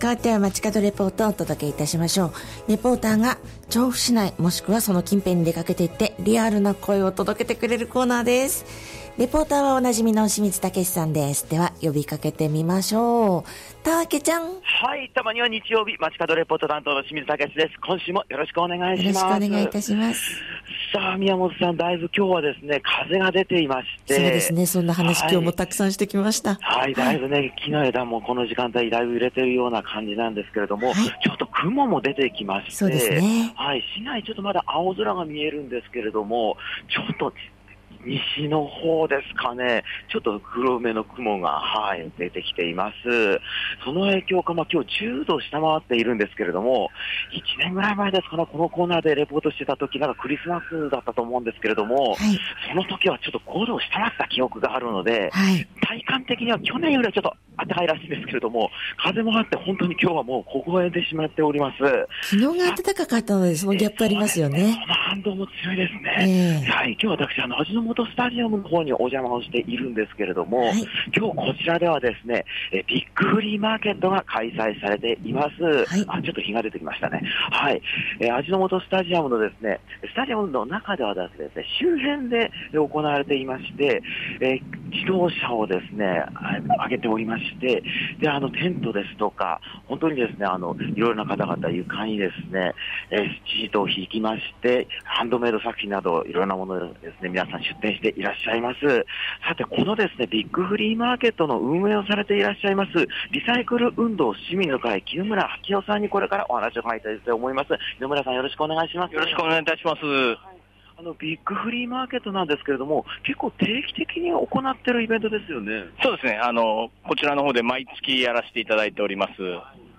雲が多めの青空の下から、お届けした本日の街角レポートは、
Bigフリーマーケット」が行われている味の素スタジアムからのレポートです！！